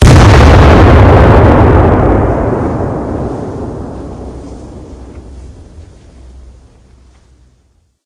loudestNoiseOnEarth.ogg